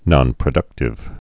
(nŏnprə-dŭktĭv)